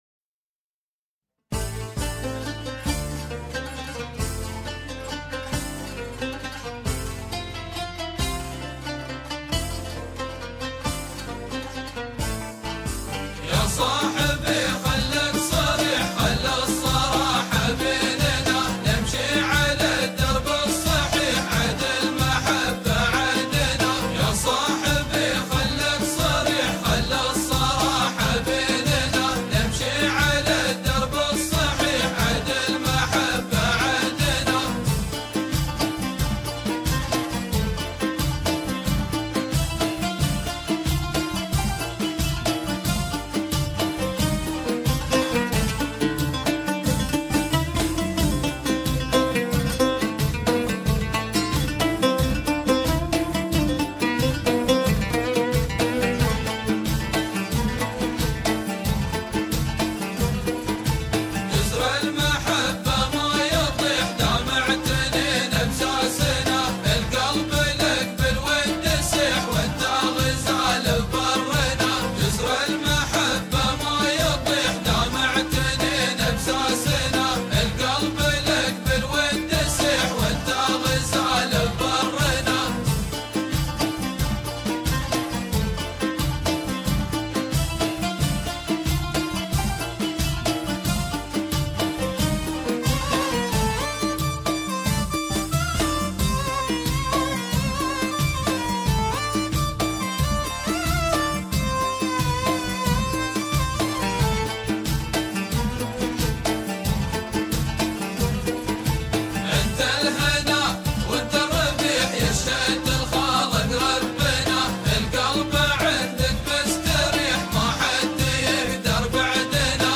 arabic traditional music